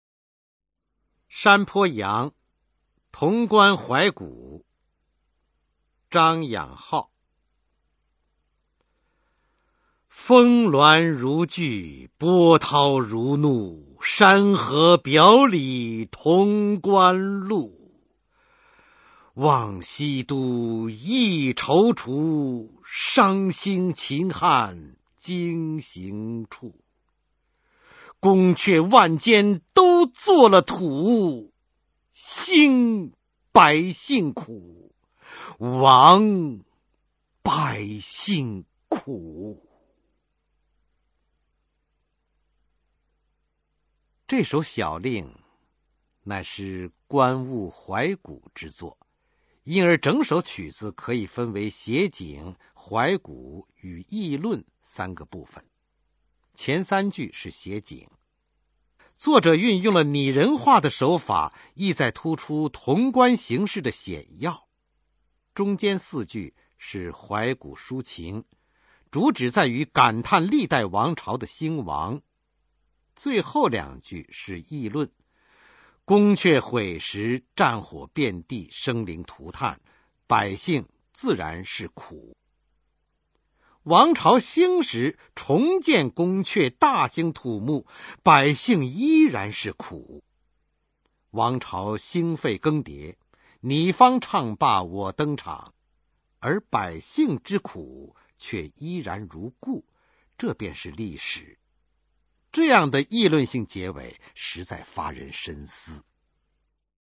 张养浩《山坡羊·潼关怀古》原文与译文（含鉴赏和朗读）　/ 张养浩